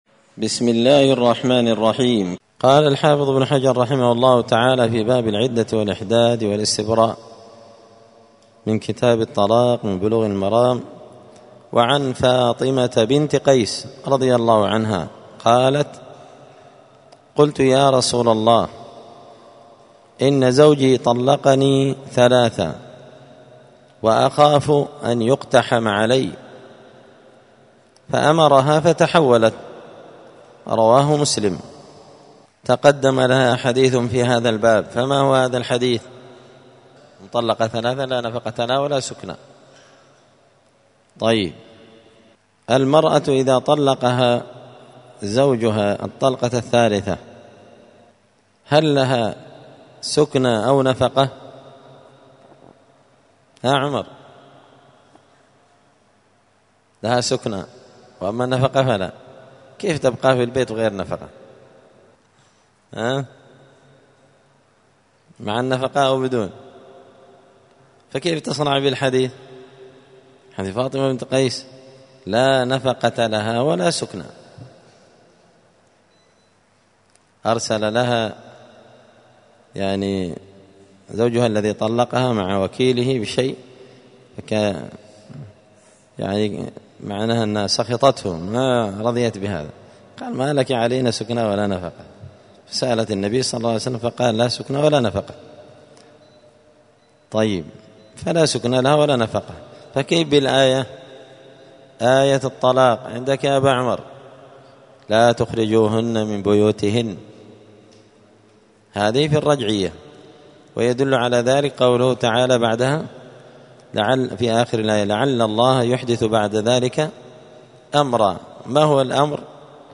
*الدرس الثالث والعشرون (23) {تابع لباب العدة الإحداد والاستبراء}*